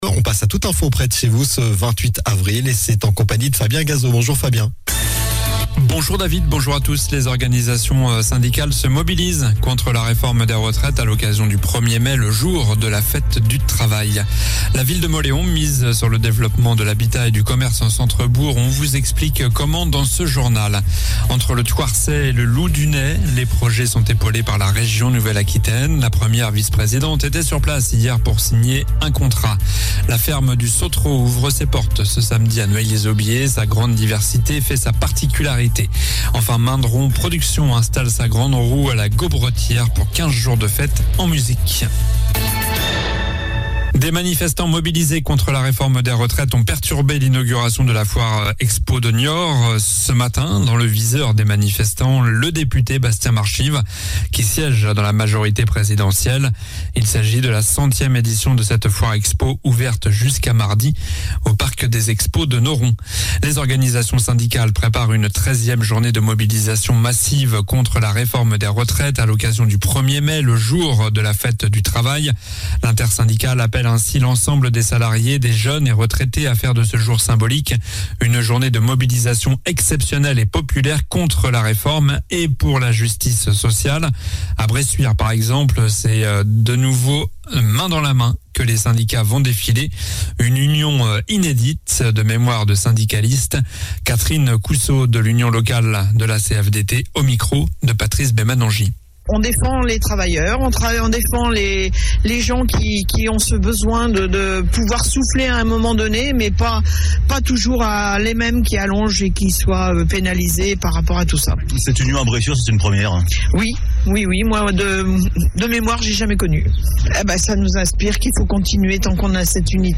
Journal du vendredi 28 avril (midi)